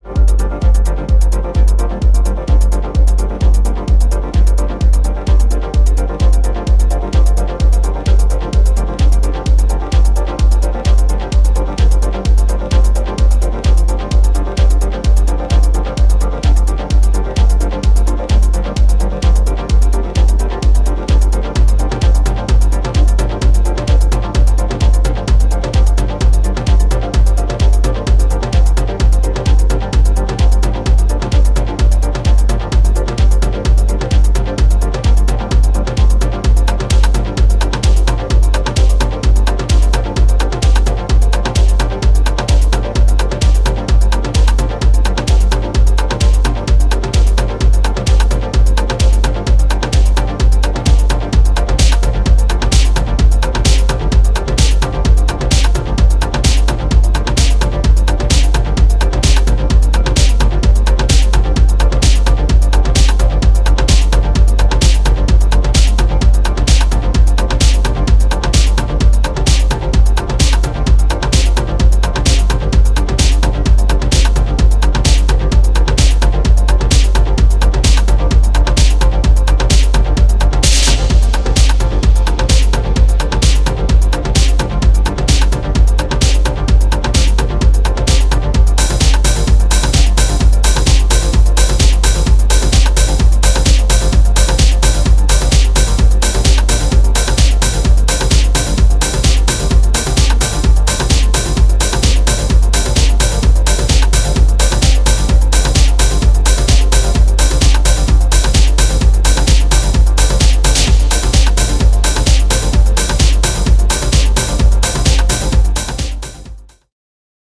角が取れつつ、しっかりとフロアを主導するビートが心地良い3トラックス、流石でございます！